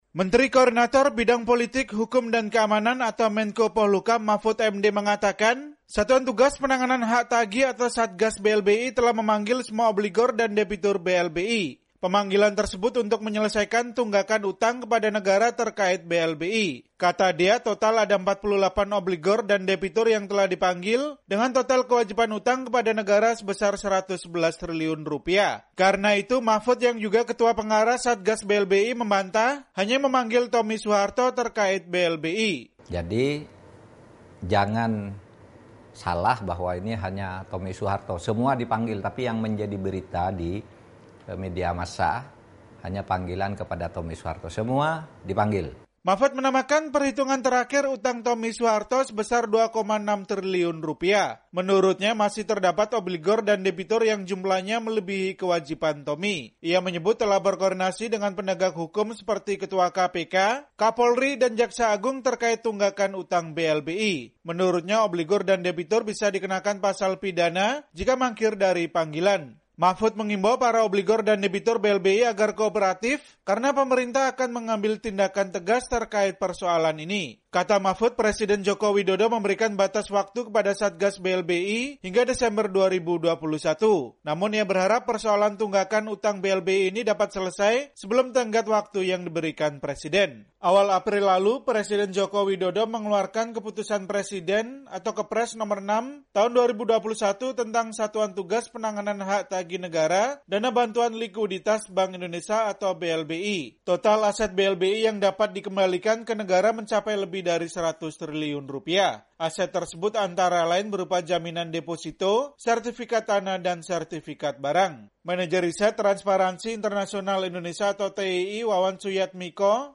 Menko Polhukam saat memberikan keterangan pers soal BLBI secara daring pada Rabu, 25 Agustus 2021. (Foto: VOA)